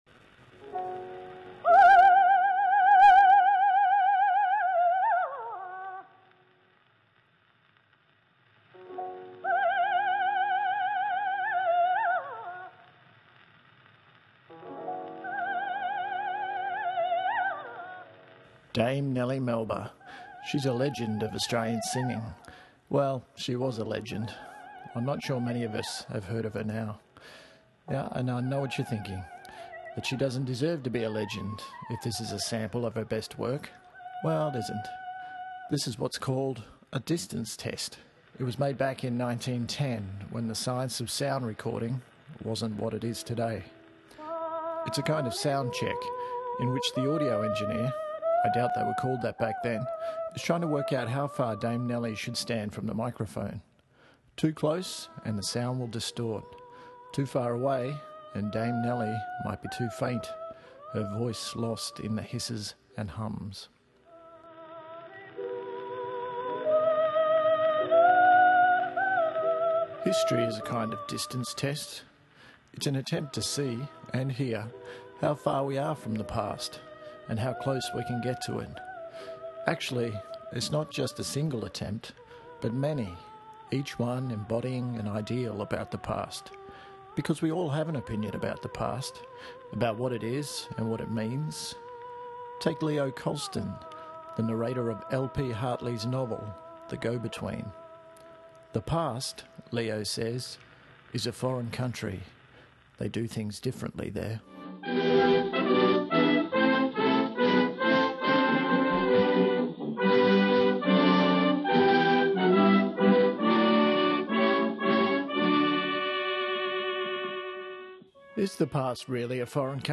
By recording strangers I meet on the street, people who agree to read an old letter aloud and to reflect on the experience, I hope to make history – a kind of live and living history that is at once both personal and public.